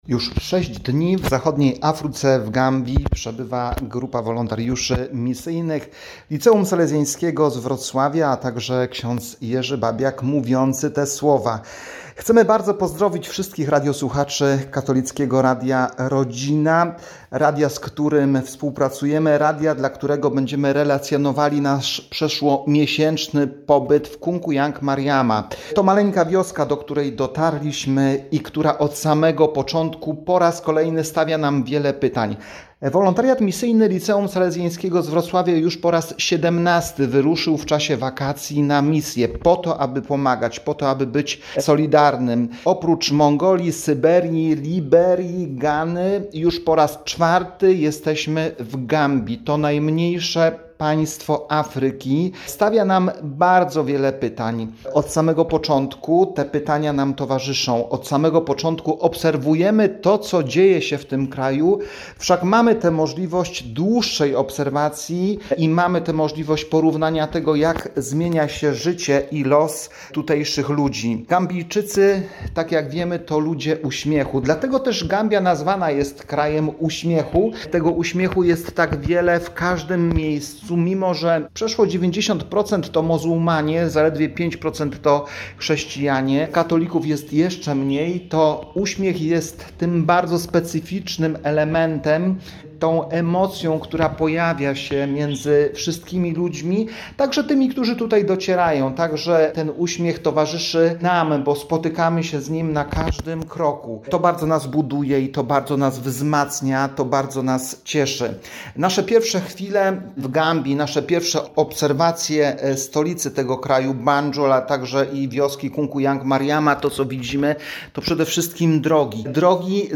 #1 Relacja z Gambii - Radio Rodzina